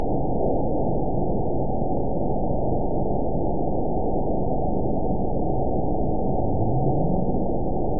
event 921983 date 12/24/24 time 09:31:02 GMT (11 months, 1 week ago) score 9.30 location TSS-AB02 detected by nrw target species NRW annotations +NRW Spectrogram: Frequency (kHz) vs. Time (s) audio not available .wav